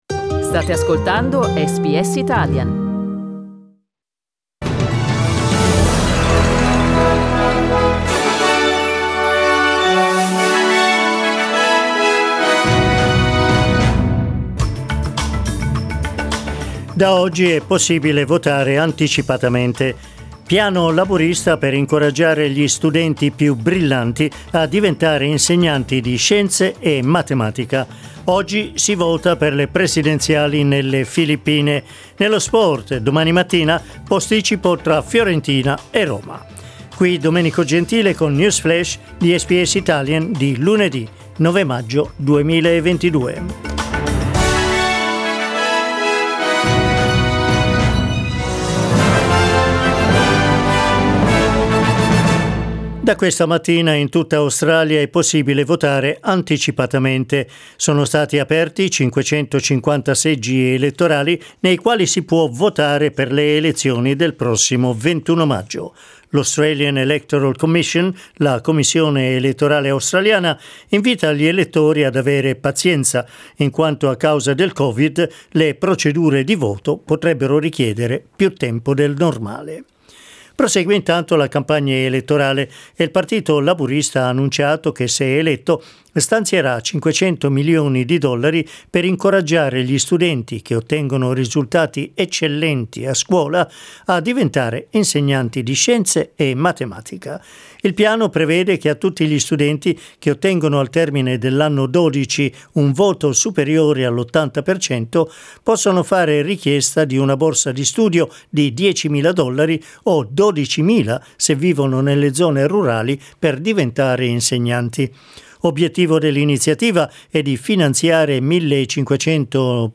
News flash lunedì 9 maggio 2022
L'aggiornamento delle notizie di SBS Italian.